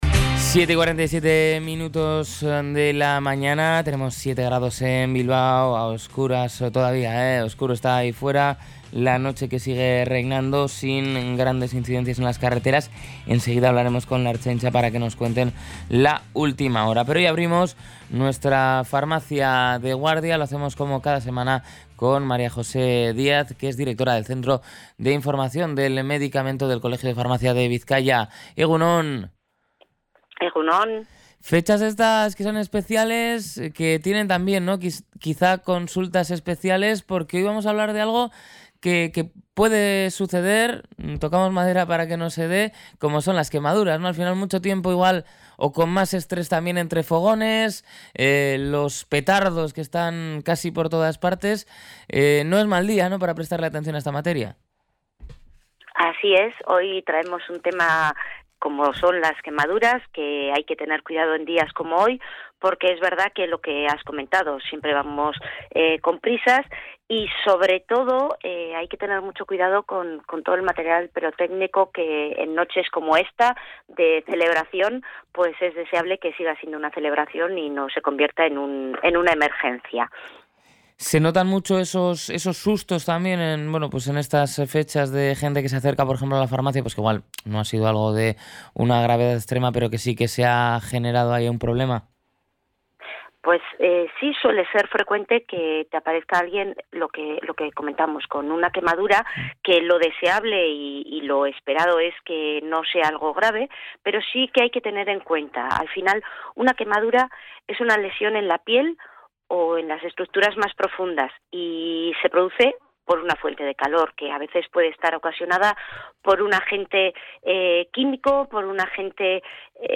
1) Enfriar con agua corriente: la invitada ha recalcado que se debe mantener la lesión bajo agua fría para reducir el dolor y evitar daño mayor.